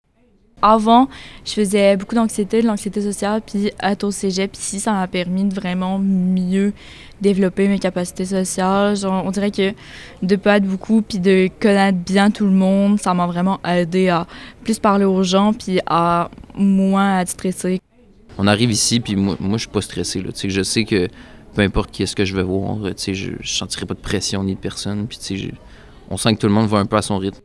CFIM 92,7 FM La radio des Iles de la Madeleine · – 2025-12-10 – Reportage sur la santé mentale des étudiants du Cégep des Îles .
La tempête fait rage dehors.
Un étudiant joue du piano.